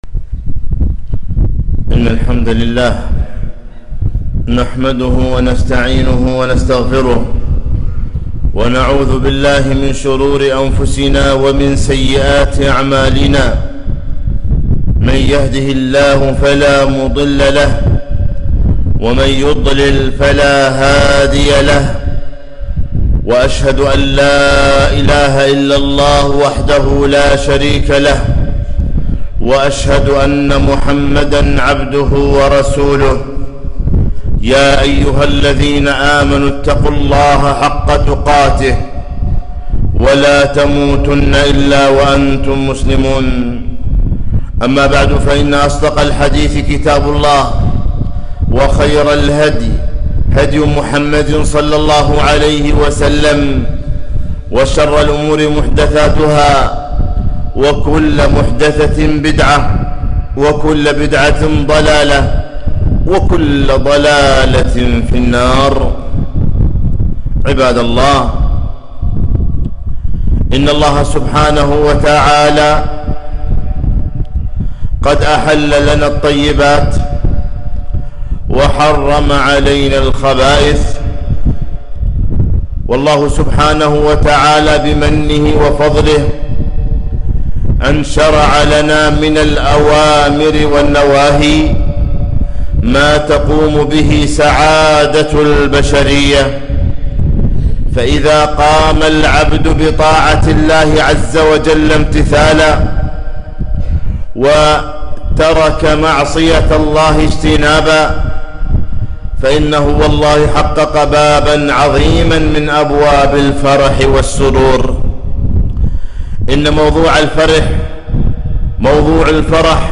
خطبة - الفرح المشروع